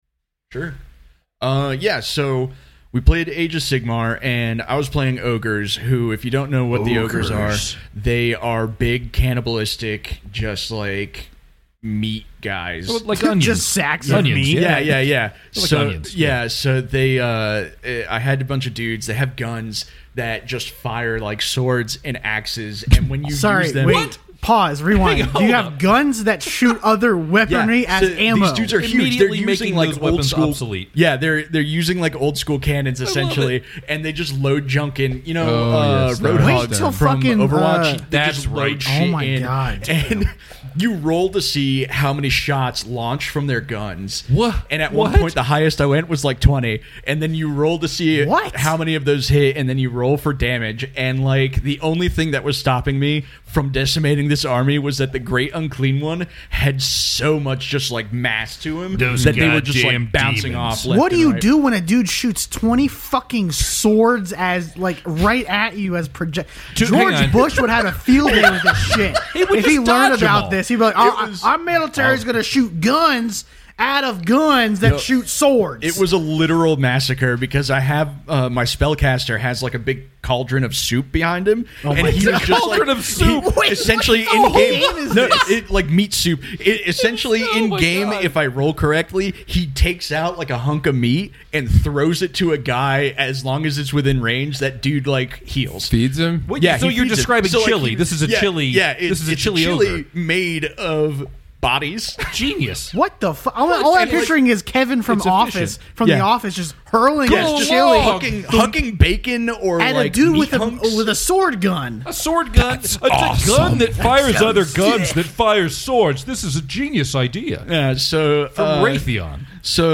We are not talking about techniques but more inspiration and that motivation to learn that new skill. Also, this is our first episode of all of us back recording in person!